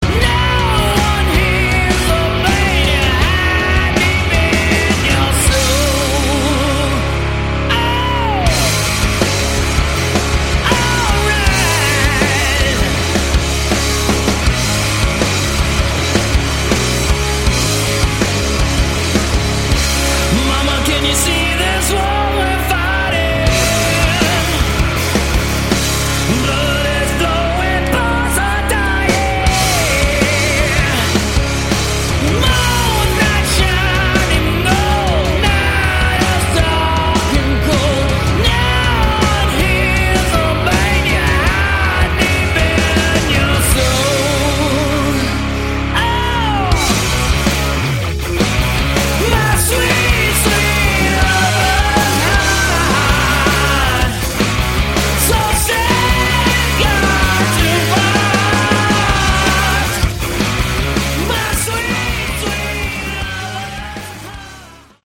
Category: Sleazy Hard Rock
vocals
guitars
bass
drums